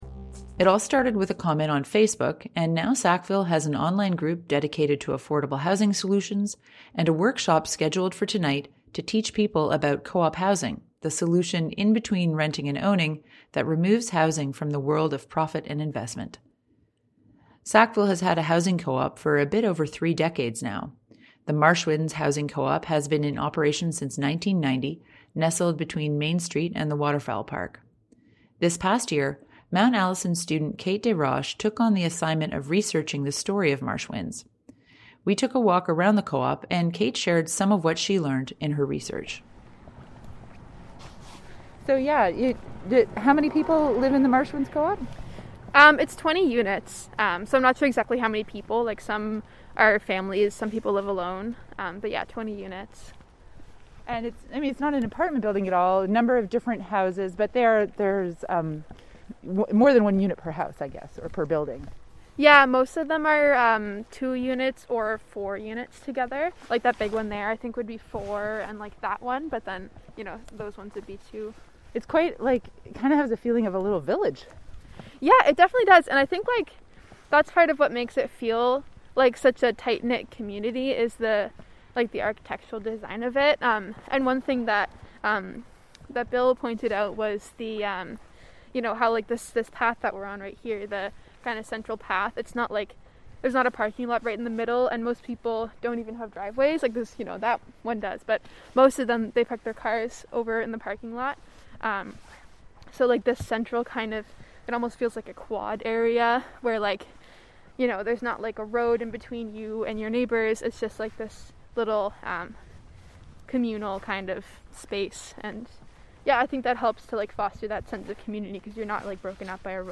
And we take a tour of the Marshwinds Housing Co-op which has provided affordable homes for 20 households for over three decades now.
Listen to the CHMA story below: